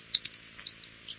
PixelPerfectionCE/assets/minecraft/sounds/mob/bat/idle2.ogg at ca8d4aeecf25d6a4cc299228cb4a1ef6ff41196e